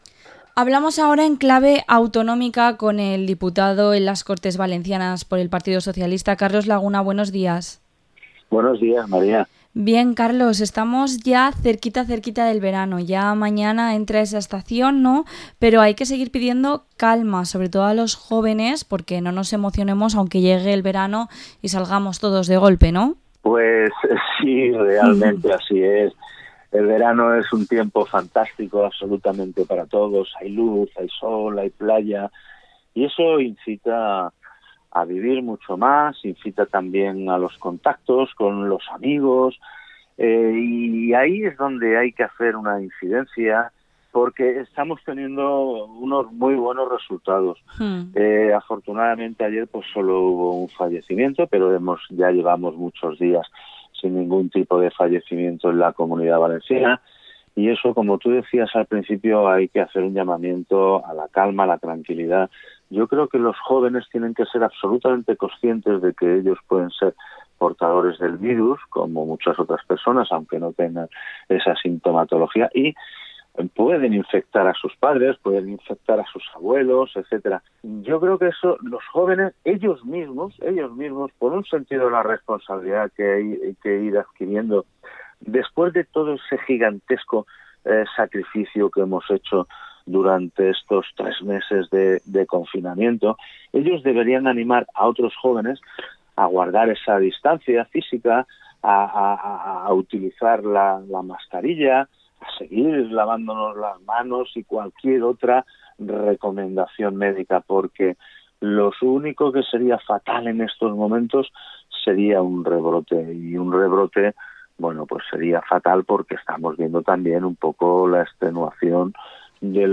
Entrevista al diputado autonómico del PSPV-PSOE, Carlos Laguna